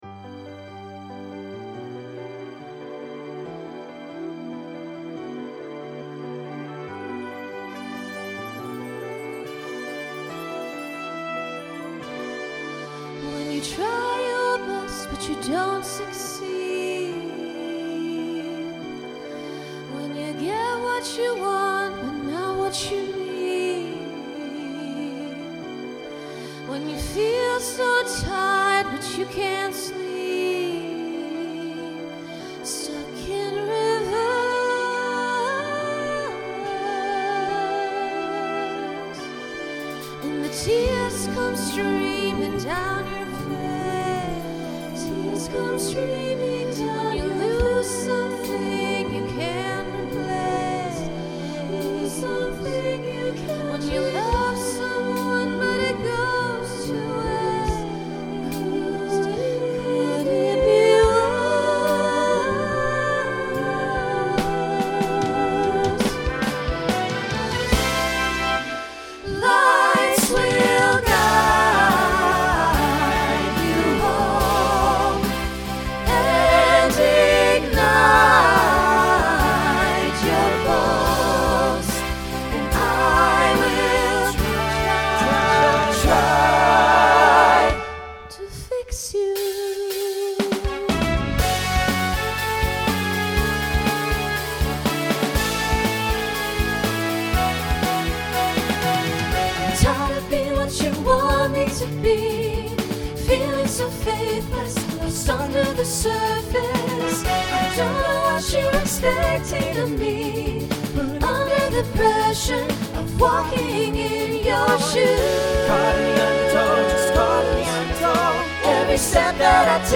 Voicing SATB Instrumental combo Genre Rock